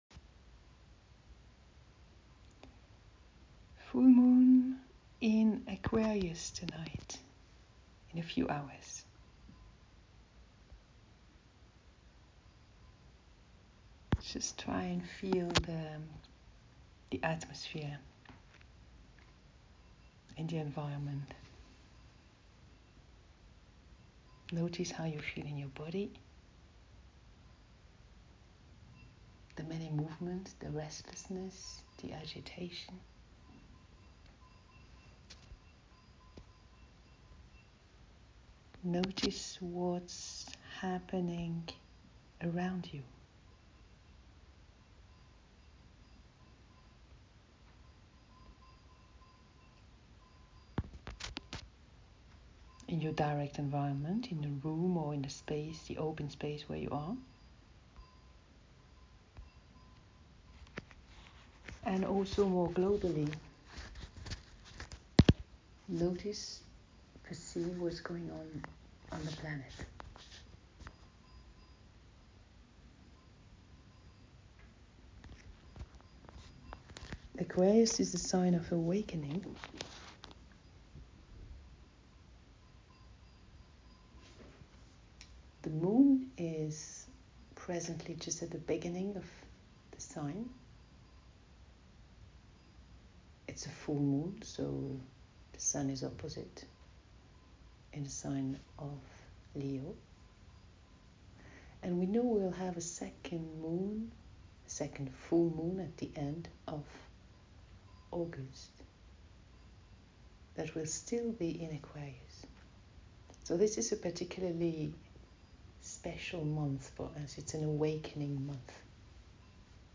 a meditation circle for the full Moon in Aquarius (it was at 3:37am GMDT on 24 July) in order for your Self to embody fully, all that is not you must be surrendered Pluto is the gate keeper, he claims your non-Self